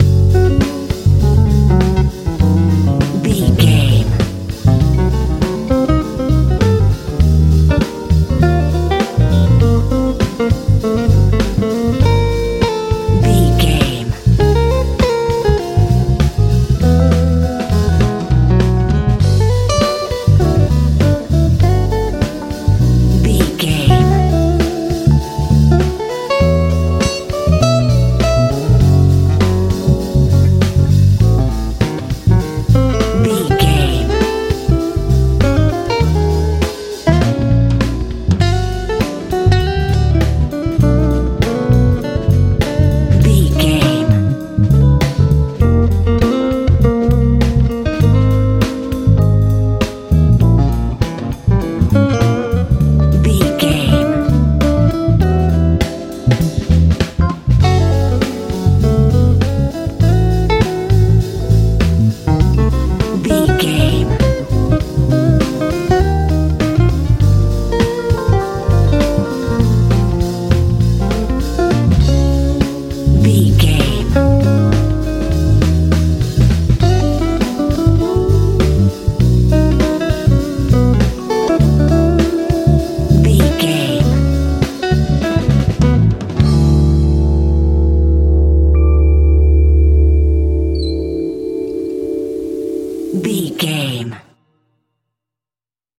modern rnb soul feeing music
Ionian/Major
D♭
funky
groovy
organ
electric guitar
bass guitar
drums
dreamy
hypnotic
mellow
melancholy